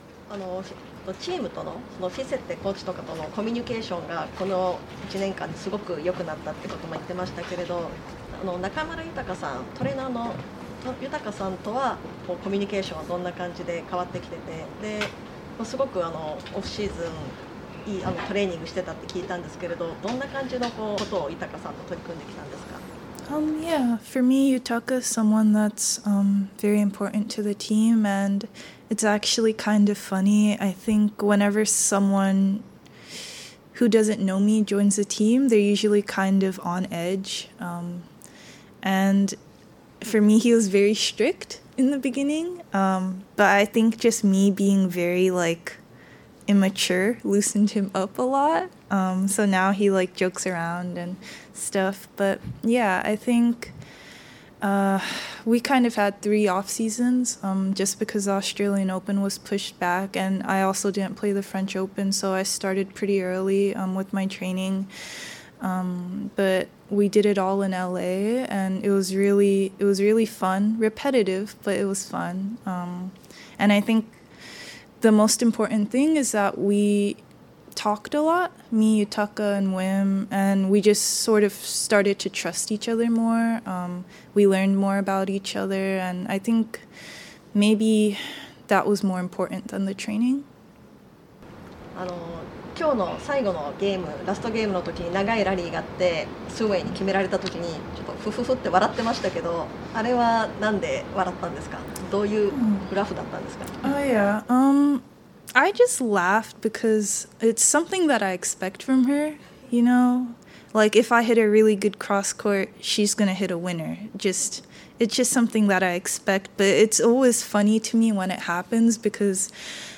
大坂なおみ、準々決勝後の記者会見
全豪オープン女子シングルスの大坂なおみ選手は準々決勝で台湾の謝淑薇選手にストレートセットで勝って準決勝へ進出しました。これはその後の記者会見でまだ準決勝での対戦相手はセリーナ・ウィリアムズ選手に決まっていませんでした。